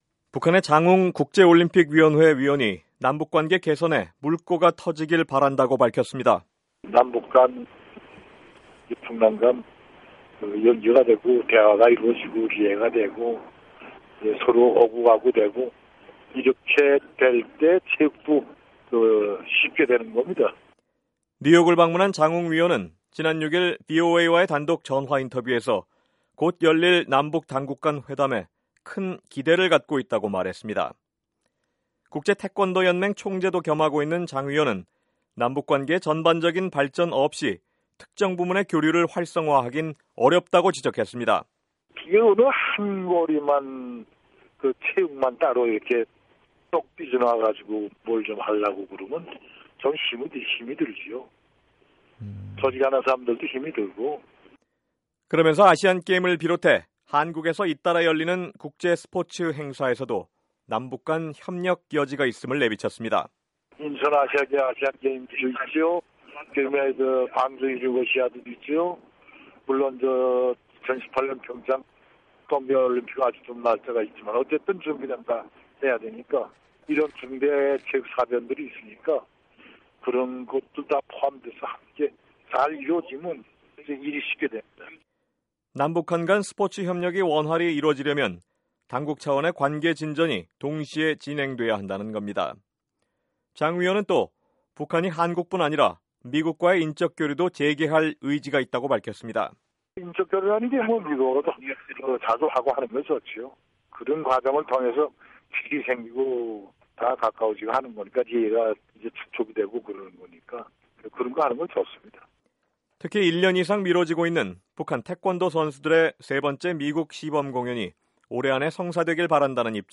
[단독 인터뷰] 장웅 북한 IOC 위원 "남북관계 개선, 미북 인적교류 재개 기대"